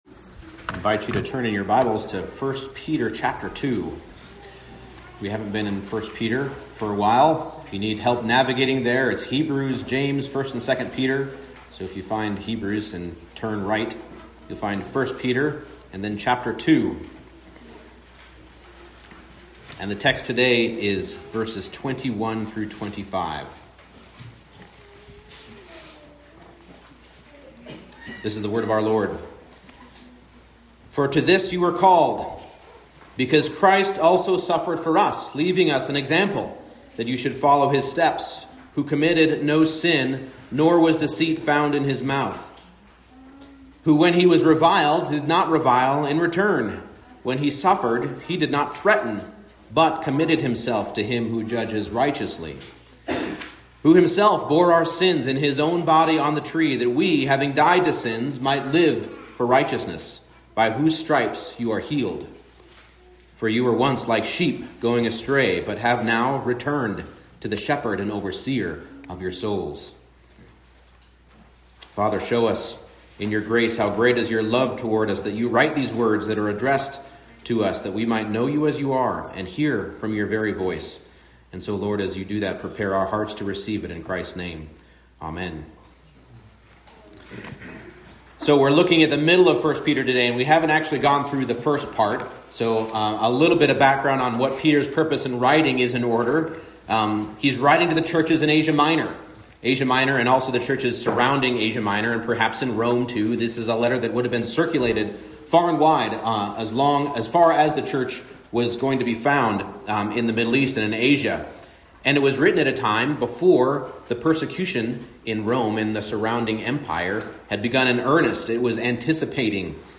1 Peter 2:21-25 Service Type: Morning Service Being a follower of Christ means living by His example.